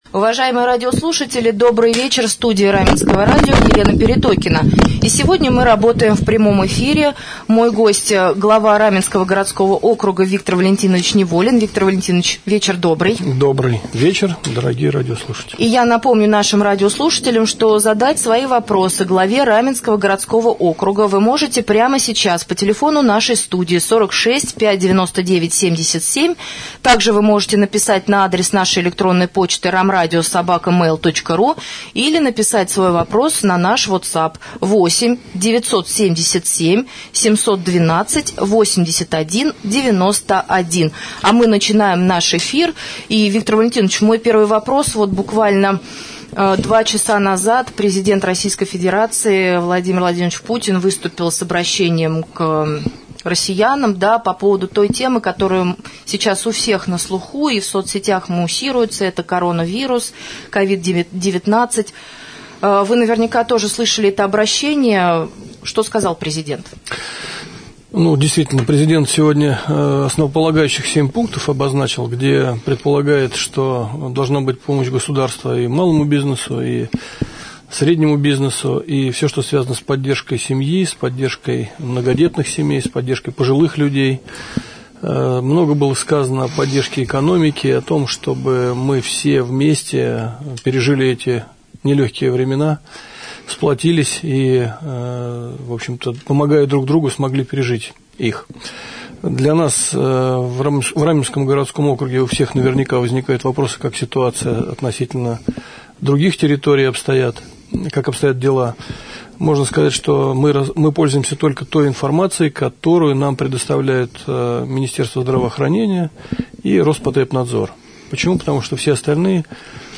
Глава Раменского городского округа Виктор Валентинович Неволин стал гостем прямого эфира на Раменском радио в среду, 25 марта.